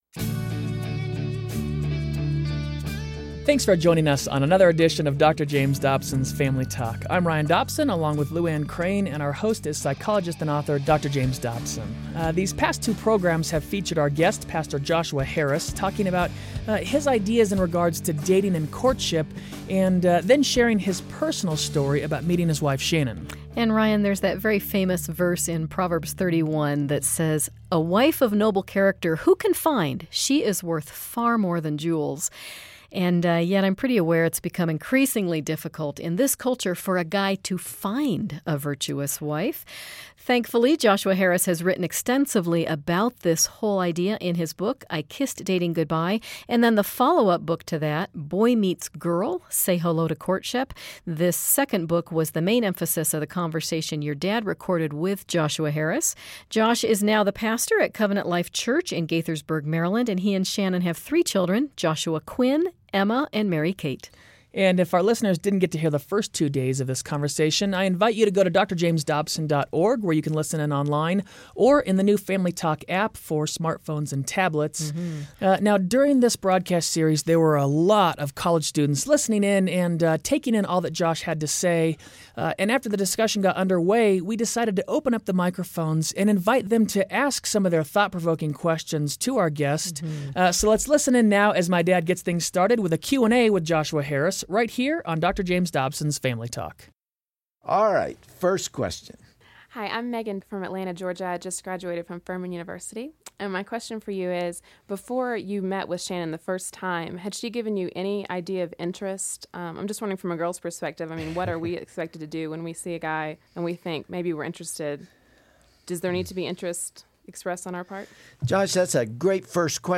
Hear some important questions from College students as they get valuable answers about the process of courtship versus dating. Learn how to handle relationships with the mindset of purity and biblical principles.